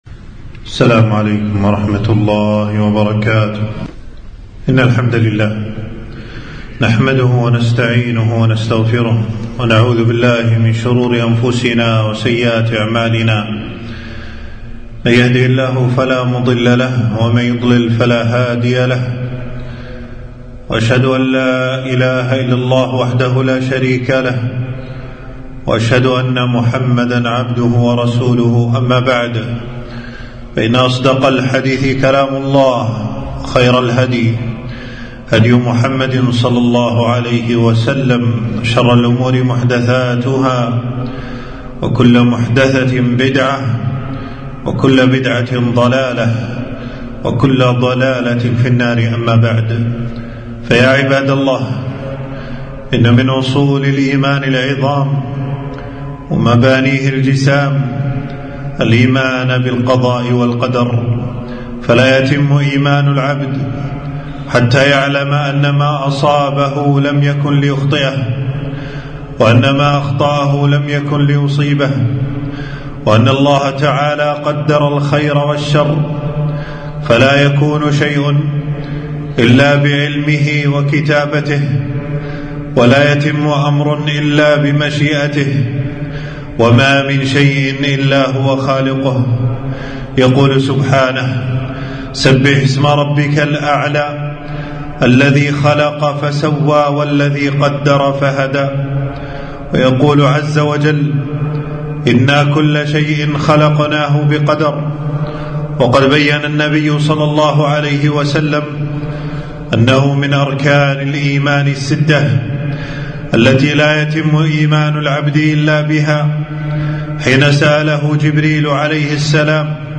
خطبة - الإيمان بالقضاء والقدر